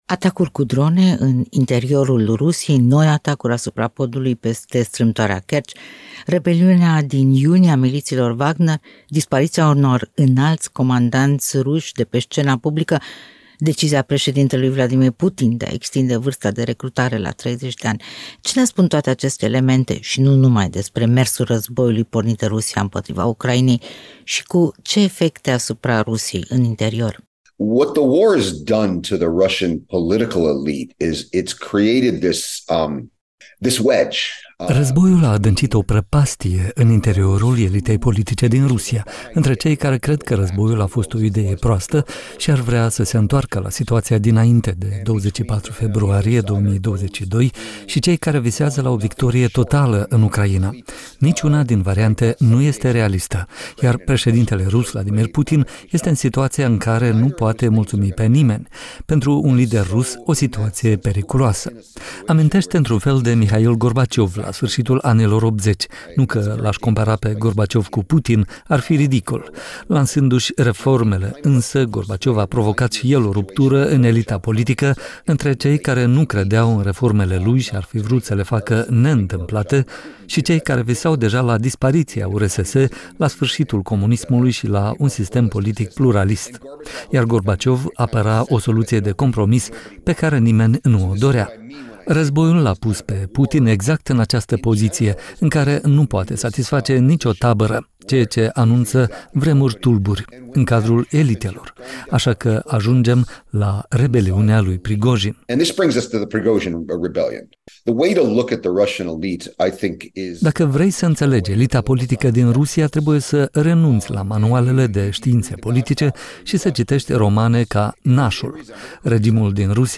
Panorama Interviu